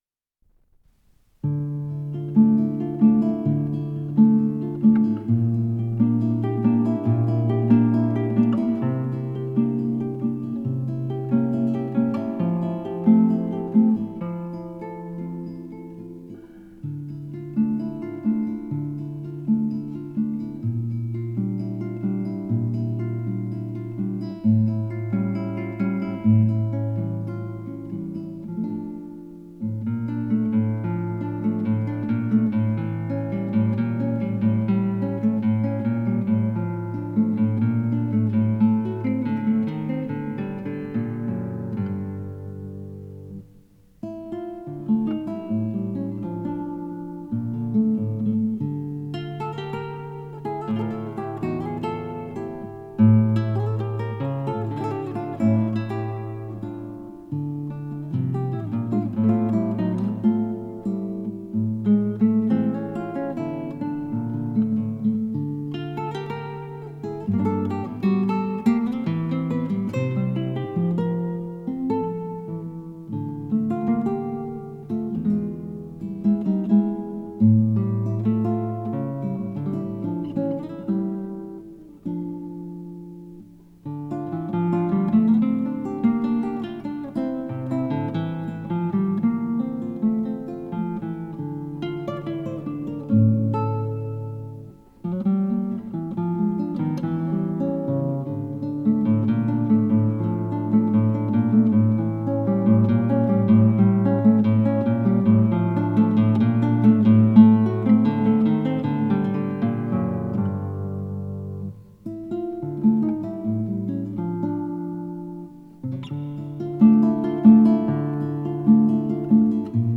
с профессиональной магнитной ленты
шестиструнная гитара